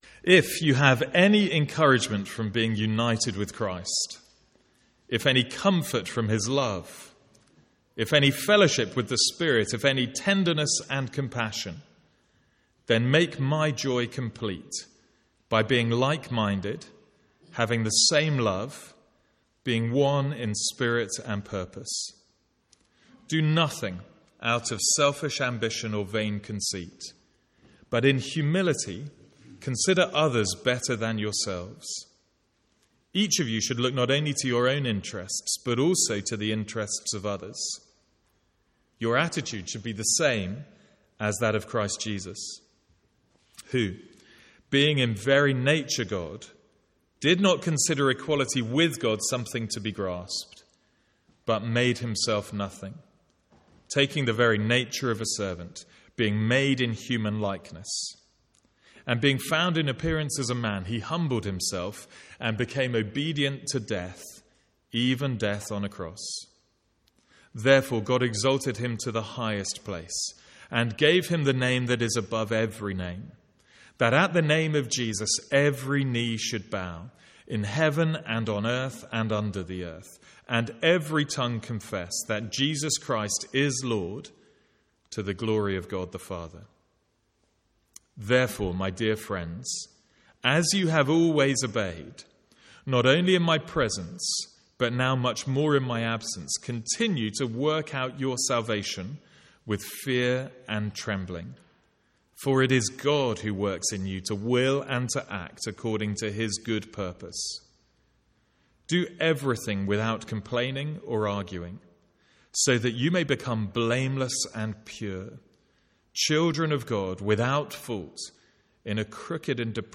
One-off Sunday morning sermon.